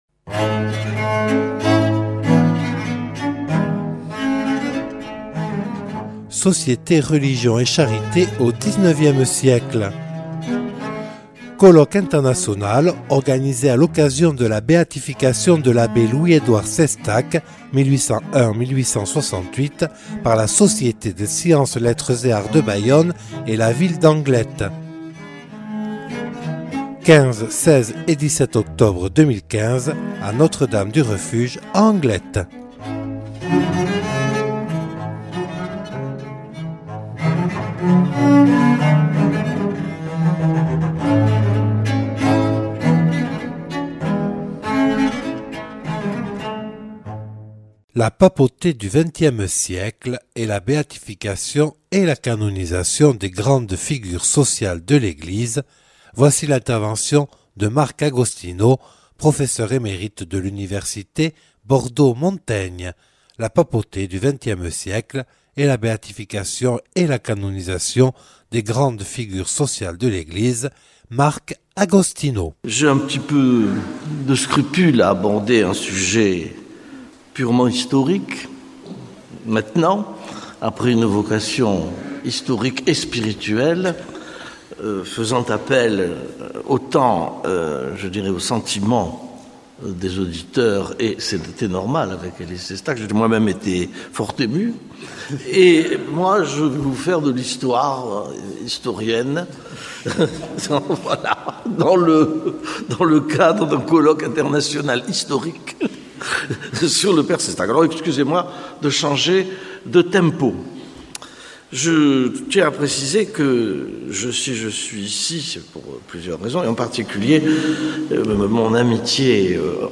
(Enregistré le 15/10/2015 à Notre Dame du Refuge à Anglet).